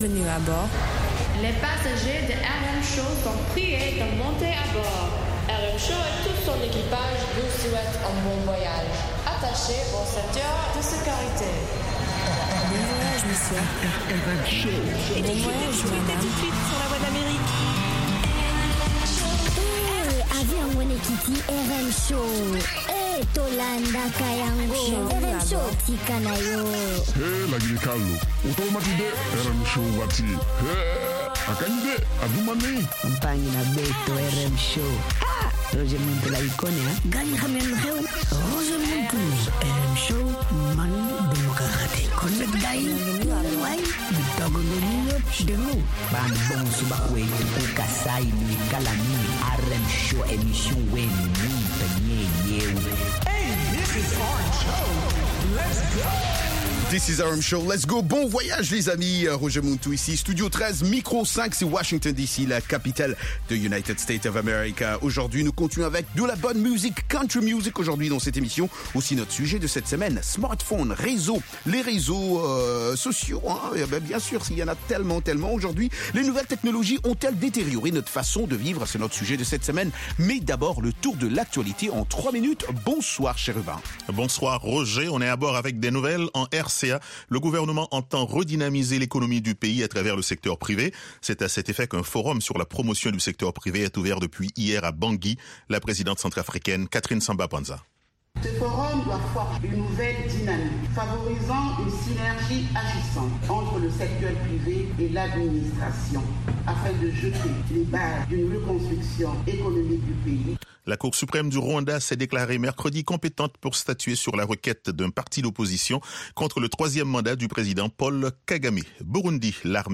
Embed RM Show -Musique internationale & comedie Embed The code has been copied to your clipboard.